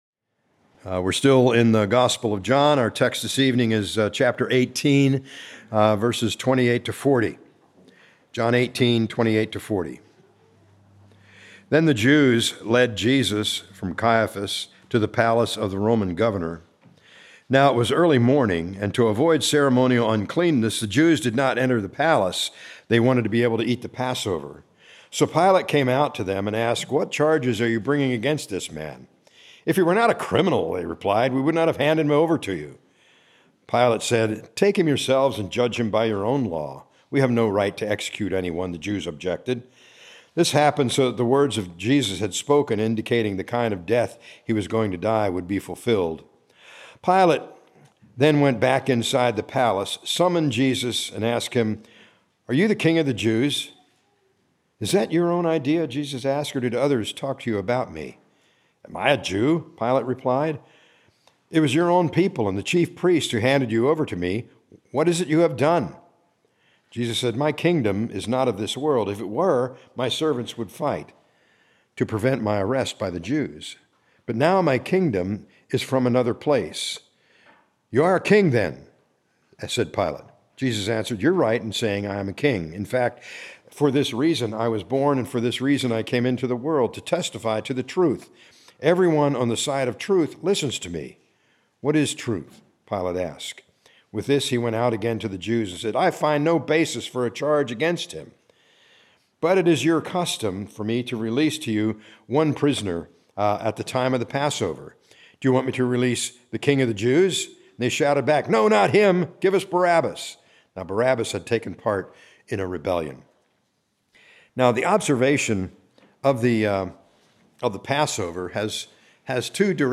A message from the series "John."